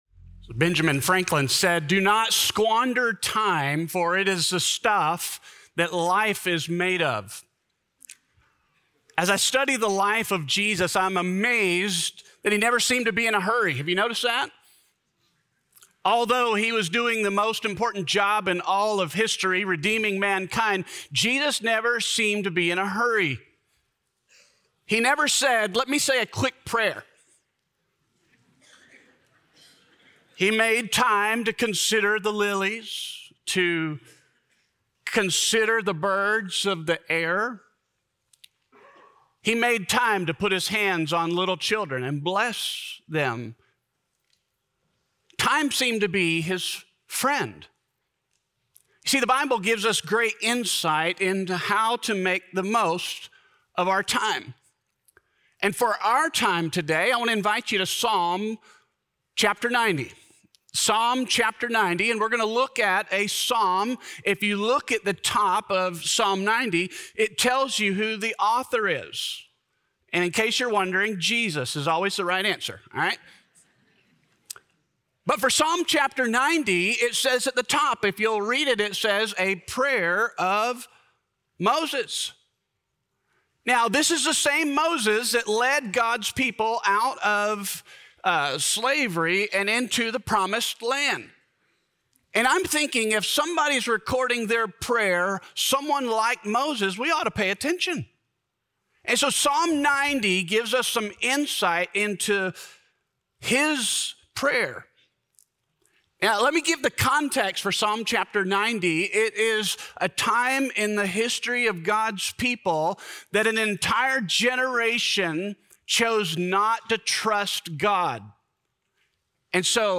Time for Change - Sermon - Ingleside Baptist Church